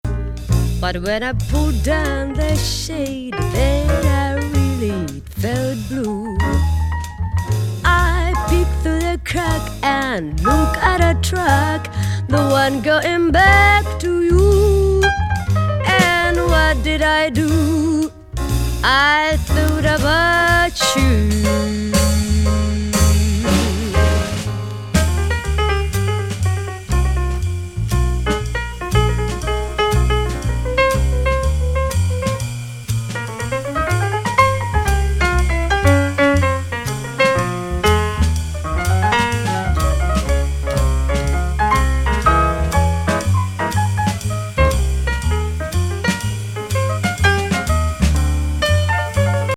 和ジャズ・ボーカル